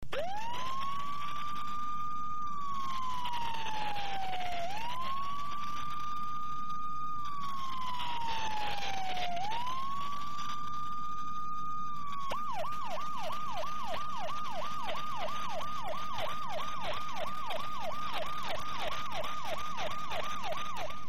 Звуки пожарной сирены, тревоги
Звук сирены пожарного автомобиля